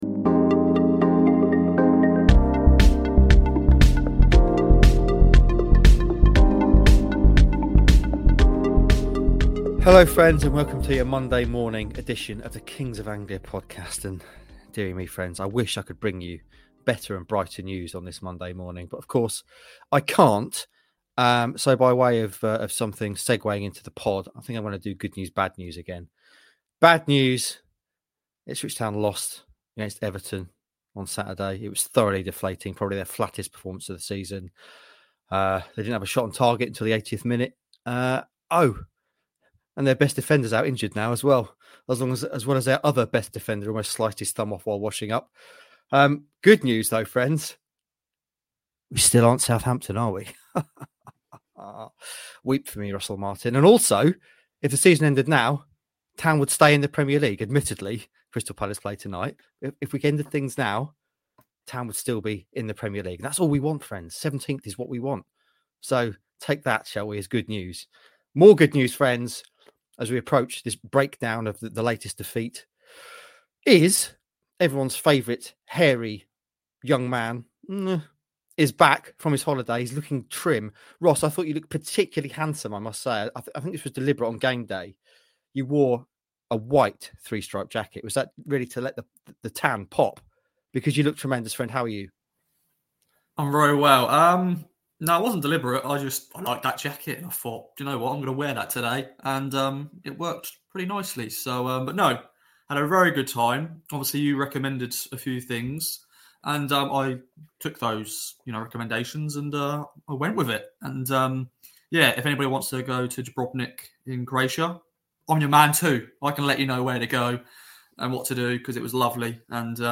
The boys chat about a big injury blow, Town’s habit of making mistakes and the big VAR debate around the Jack Clarke penalty/non penalty. We also hear from a vexed Kieran McKenna plus a slightly more chilled Dara O’Shea and talk through some options to shake things up which the Town boss may consider ahead of the trip to Brentford.